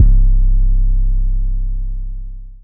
Sig808_YC.wav